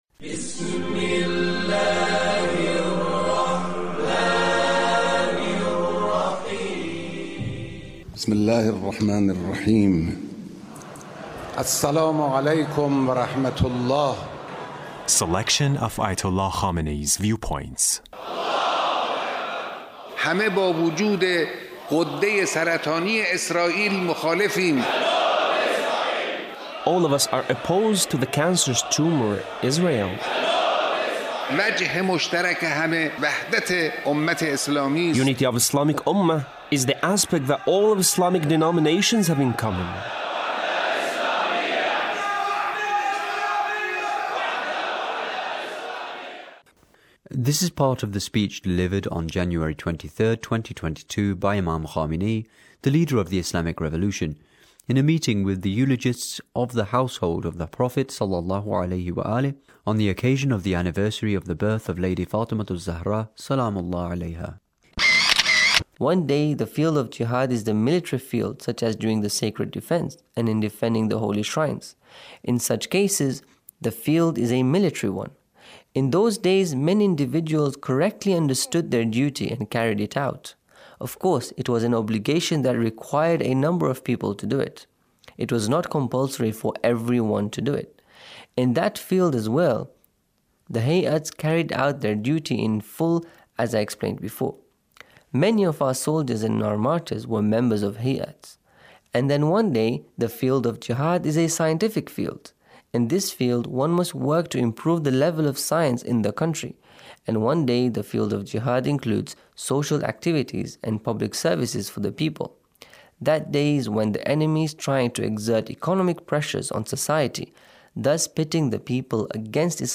Leader's Speech on a Gathering with eulogists of the Household of the Prophet